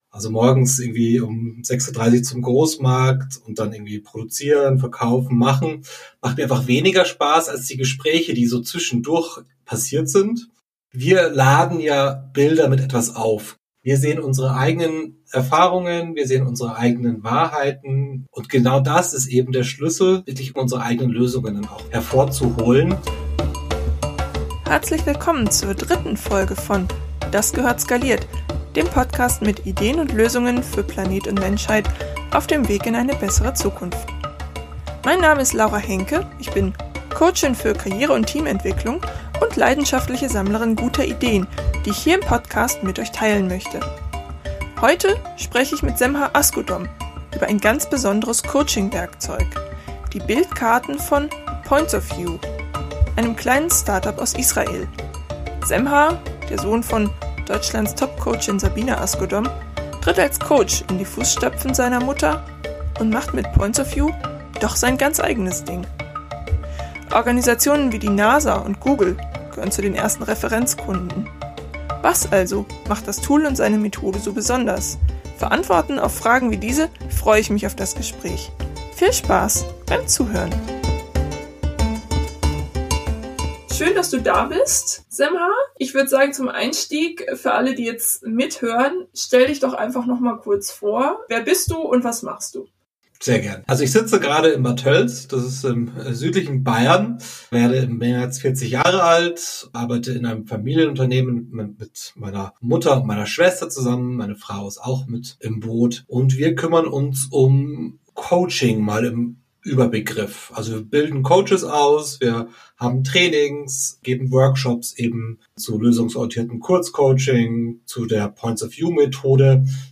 Was also macht das Tool und seine Methode so besonders? Für Antworten auf Fragen wie diese freue ich mich auf das Gespräch.